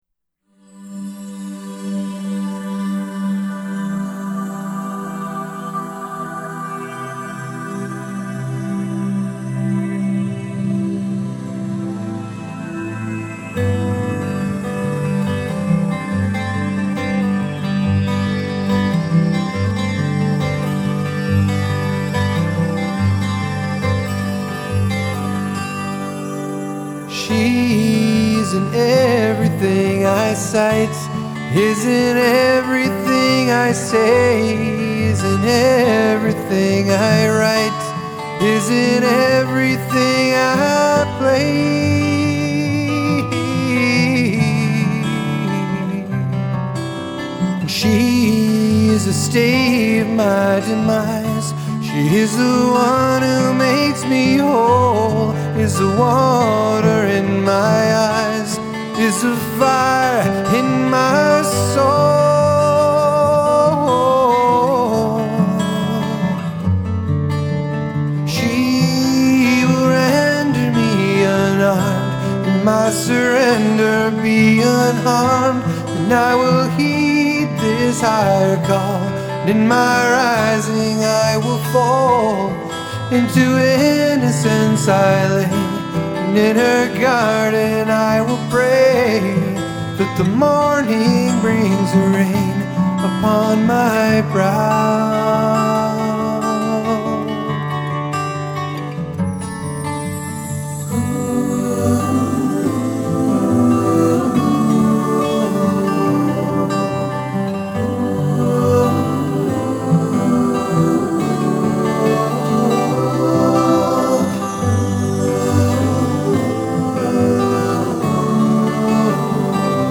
钢琴、竖琴、大提琴、手风琴、爱尔兰笛